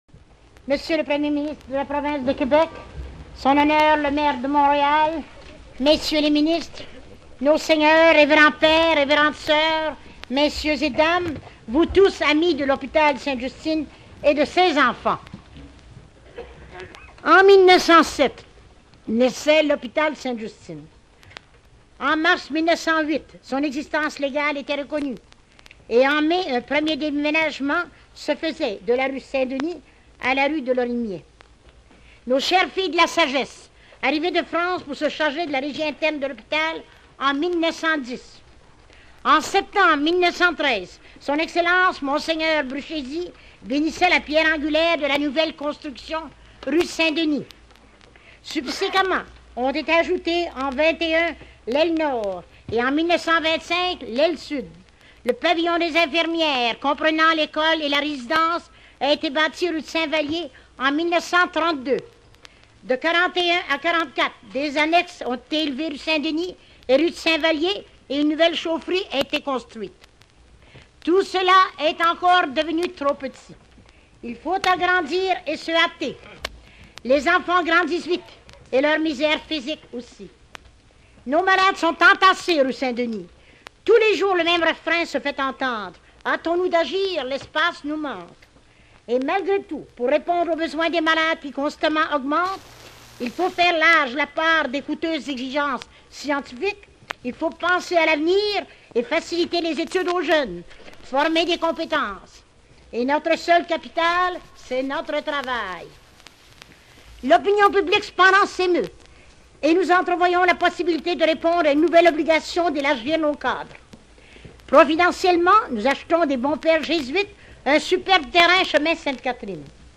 Toute la population de la province peut écouter la cérémonie, diffusée à la radio.
Extrait du discours de Justine Lacoste Beaubien
ExtraitDiscoursJustine-1951.wma